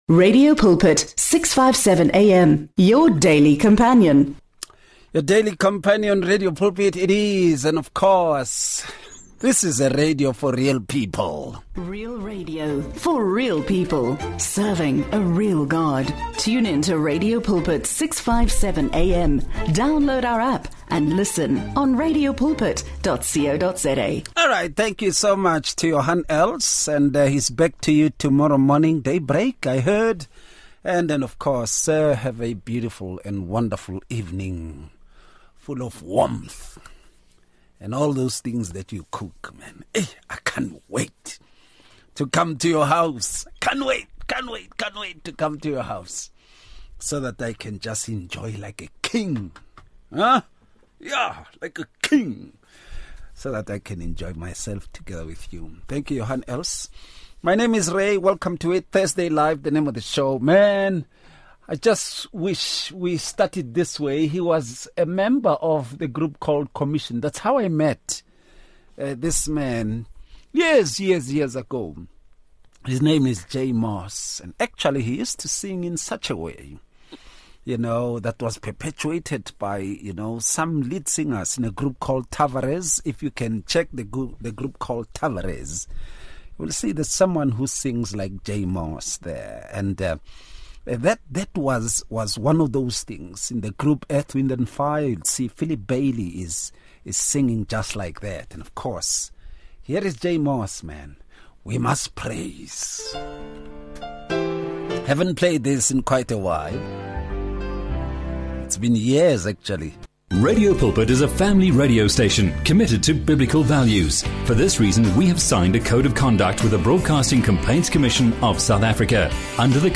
They emphasize that holiness involves both moral purity and a deep spiritual commitment to God’s will. The panelists share insights on how holiness is cultivated through daily spiritual disciplines and reliance on the Holy Spirit. This conversation invites listeners to understand holiness not just as a standard, but as a transformative journey in the Christian faith.